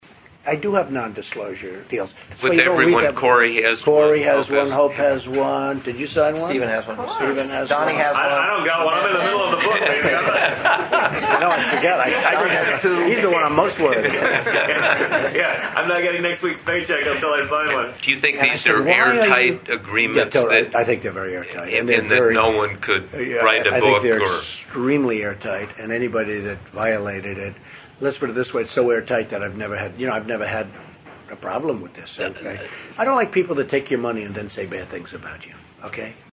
The audio gives an inside glimpse of Trump’s inner circle, like an exchange from 2016 when Trump was asked whether he expects government employees to sign non-disclosure agreements, and his son chimed in.